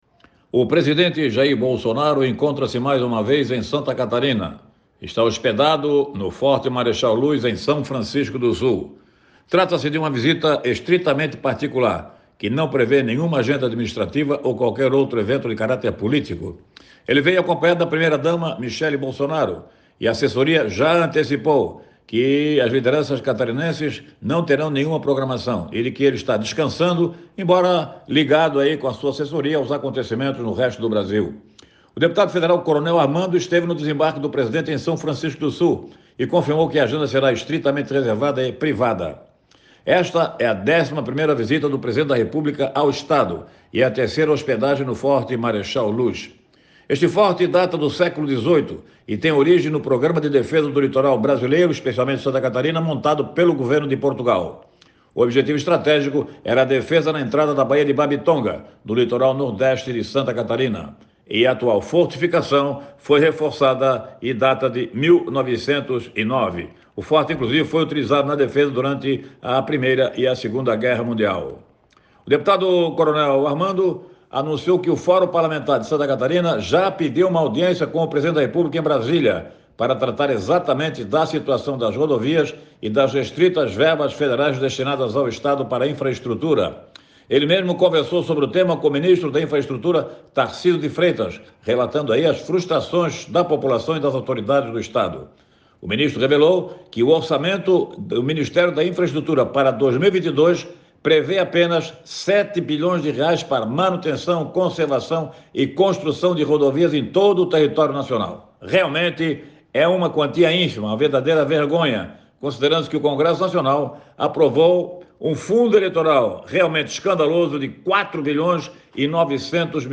comentário político